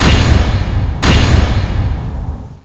sfx-notguilty.wav